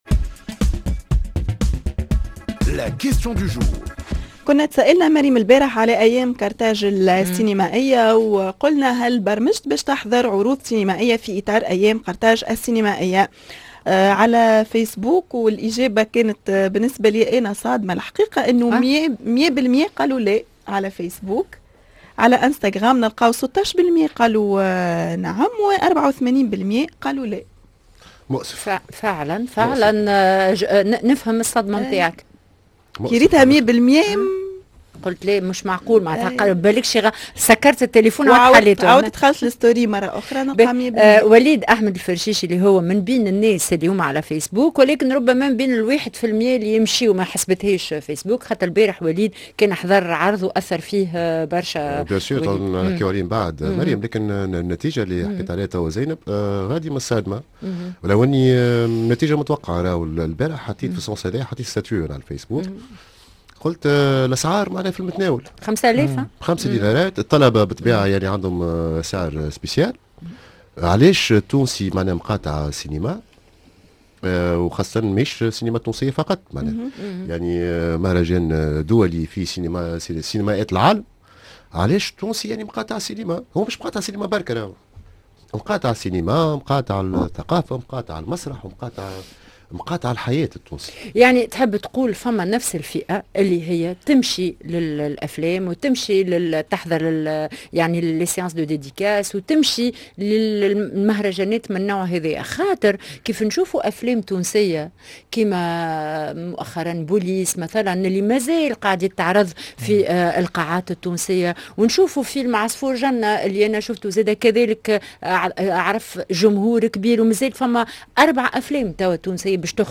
سؤال اليوم : هل برمجت باش تحضر عروض مسرحية في أيام قرطاج السينمائية كيفاش كانت إجابات التوانسة